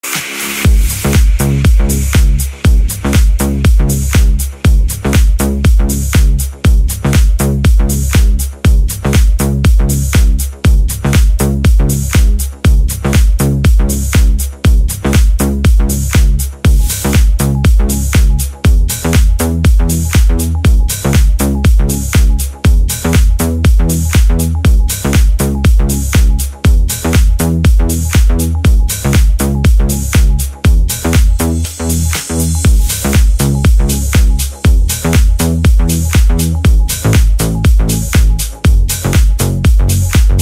Categoria POP